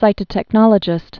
(sītə-tĕk-nŏlə-jĭst)